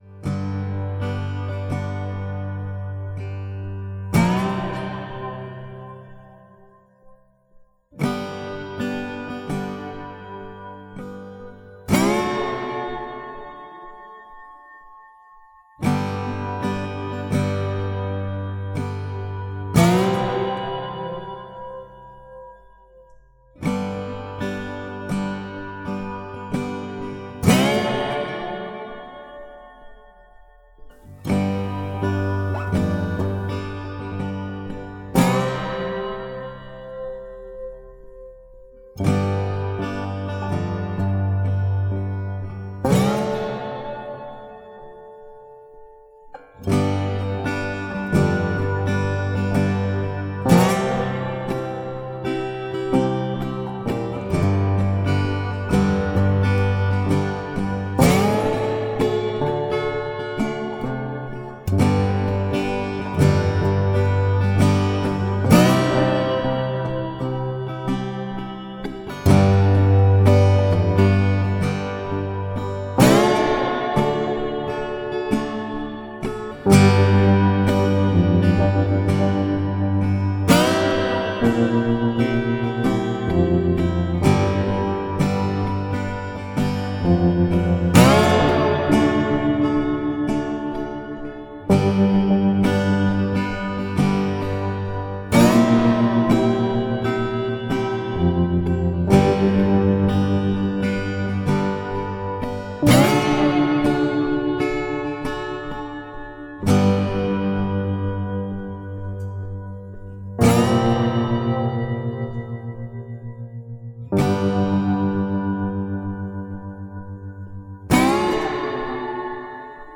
Relaxing Slow Instrumental Blues